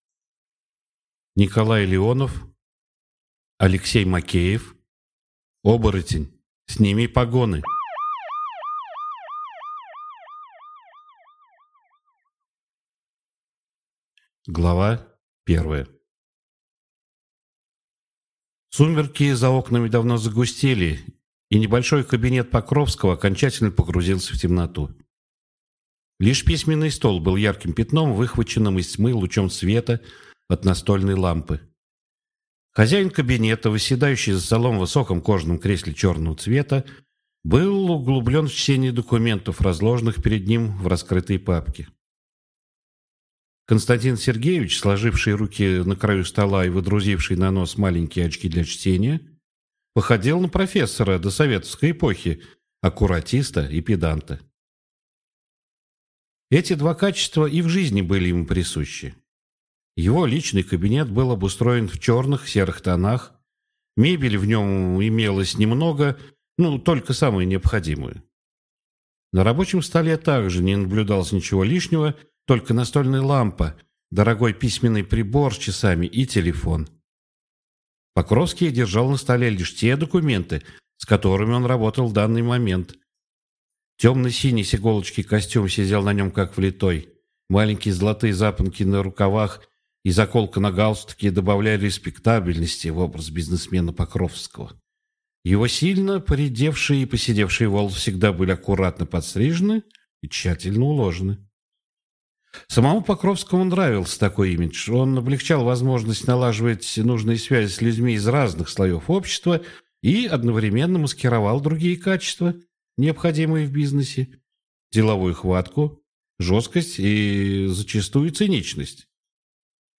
Аудиокнига Оборотень, сними погоны!